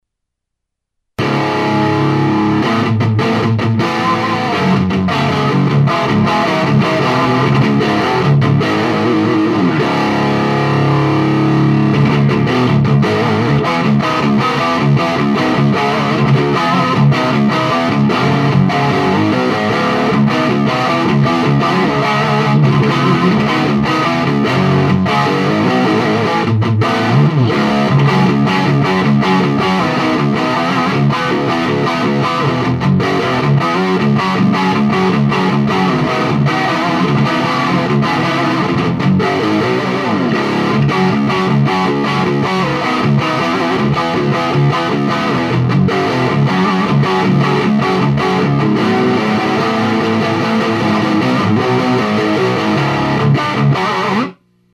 A strat,and used my PC sound card to record with,just a SM 58.